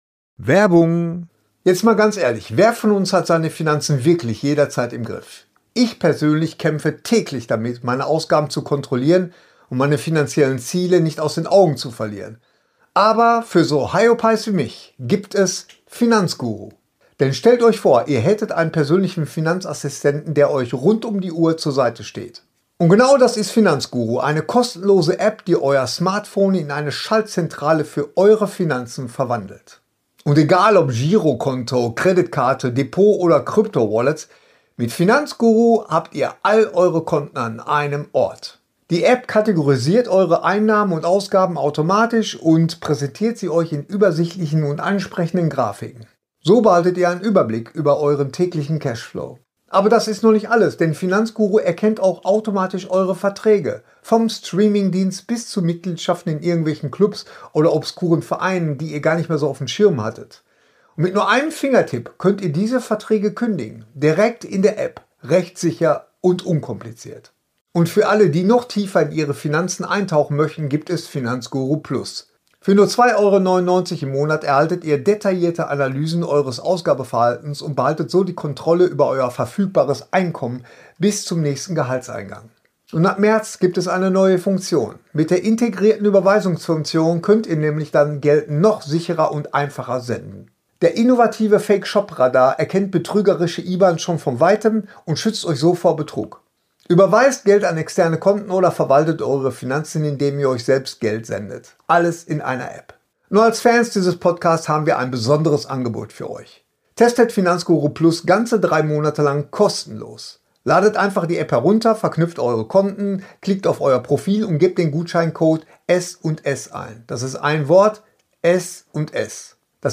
reden, diskutieren und streiten mal wieder über die Dinge, die in der Nerd Welt Wellen schlagen.